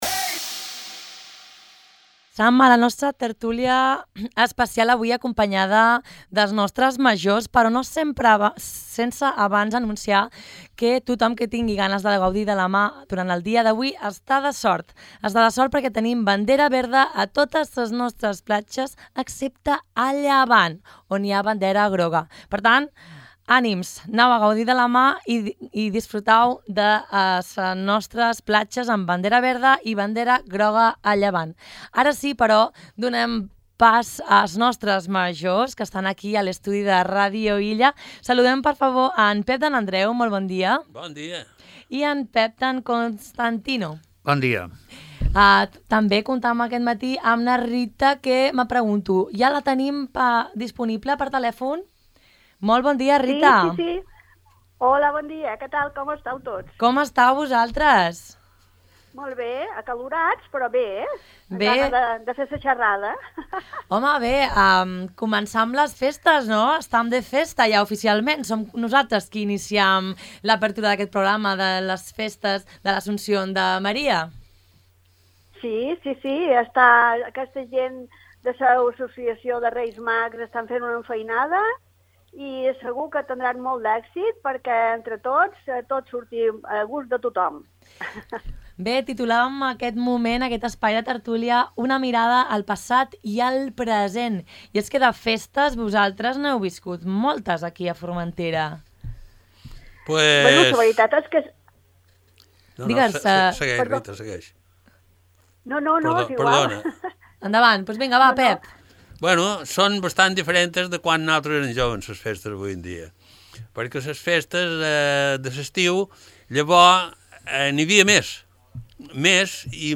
Encetam la programació de les festes de la Mare de Déu d’Agost 2022 amb una tertúlia especial.